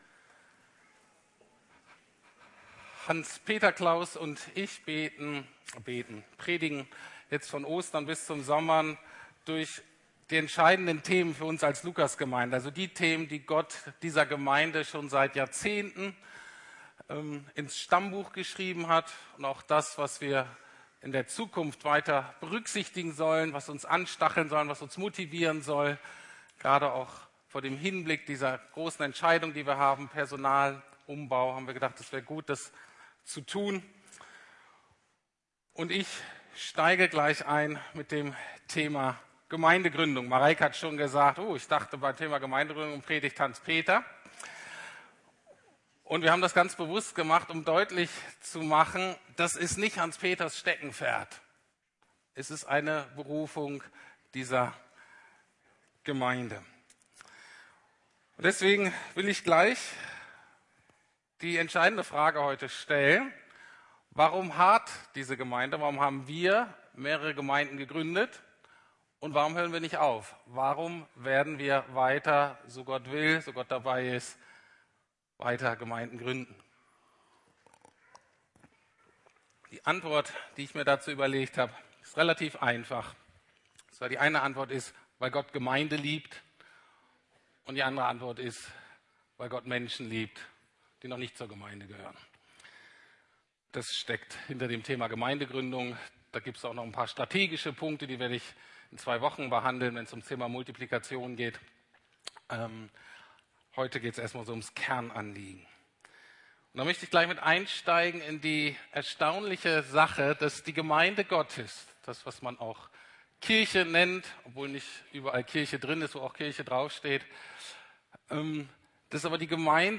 Predigten der LUKAS GEMEINDE Podcast Das Erbe der Zukunft - Gemeindegründung